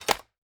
Free Fantasy SFX Pack
Chopping and Mining
chop 4.ogg